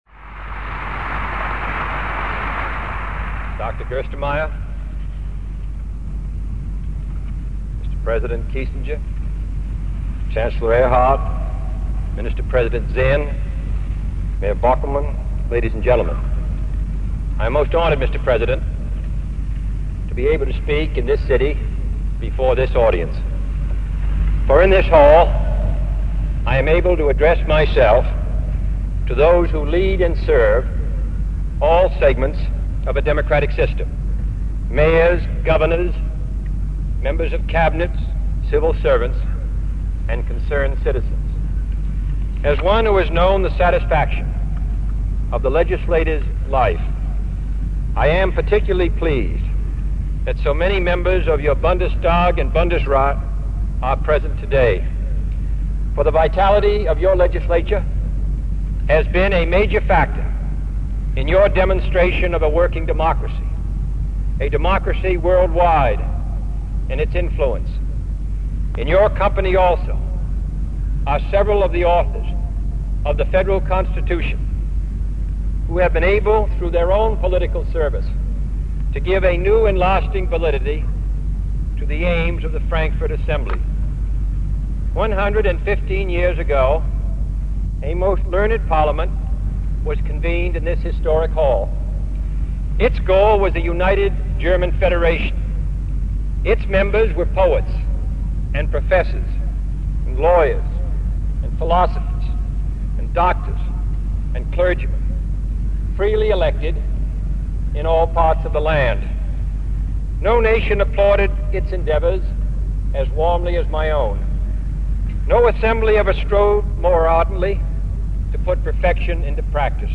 President John F. Kennedy delivers an address at the Paulskirche in Frankfurt am Main, Germany. He speaks on the hope of peace, freedom and unity born out of the United Nations, NATO and future international discussions.
International cooperation Liberty Peace Material Type Sound recordings Language English Extent 00:33:17 Venue Note Broadcast on June 25, 1963.